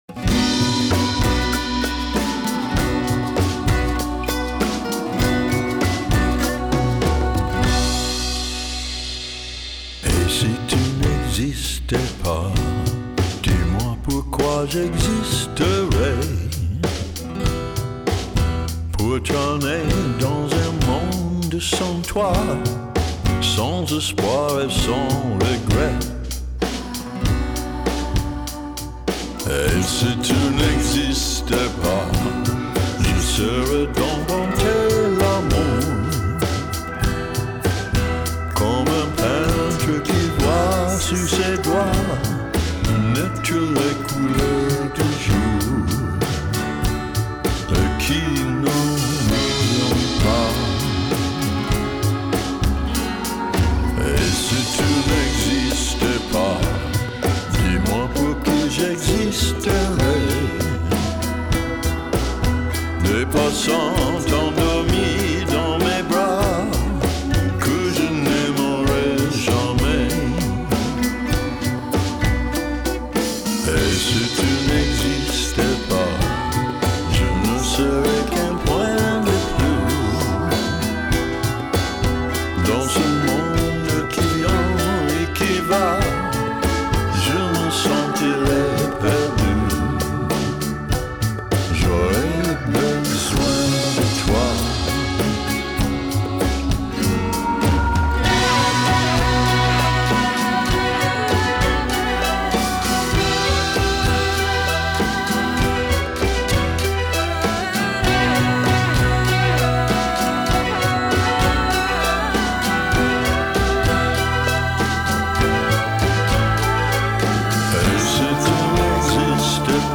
По-моему, очень символично, тонко и естественно.
рок-музыка